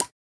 LeverB.wav